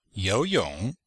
Cours-de-chinois-S2-L3-you2yong3.mp3